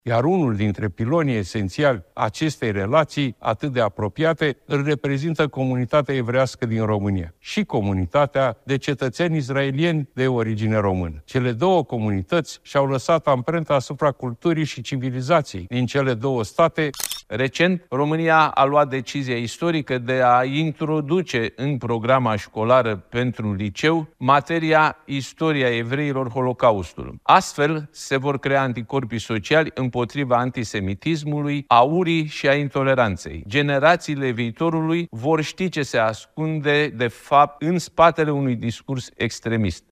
Iar premierul și președintele Senatului au transmis mesaje video înregistrate care au fost difuzate în Parlament.
La rândul său, președintele Senatului a transmis un mesaj video prin care a amintit de introducerea materiei „Istoria evreilor. Holocaustul” la clasele a XI-a și a XII-a.